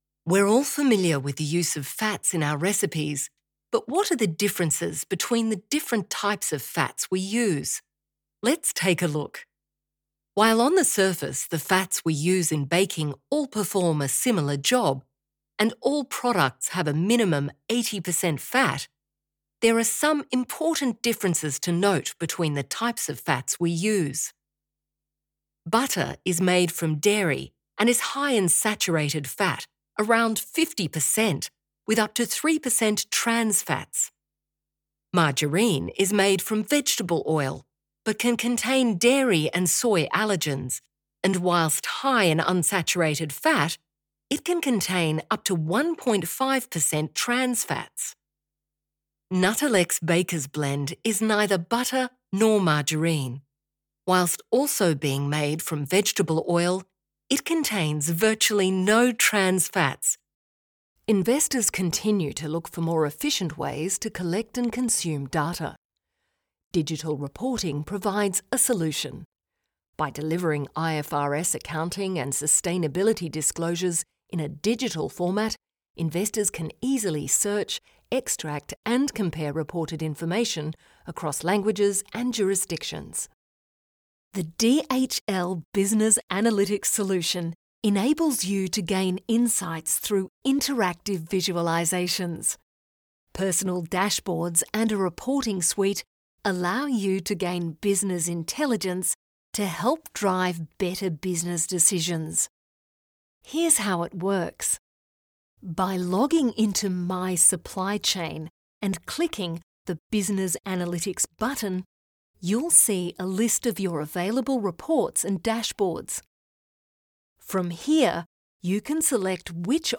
Explainer & Whiteboard Video Voice Overs | Instructional YouTube Voice Over | Male & Female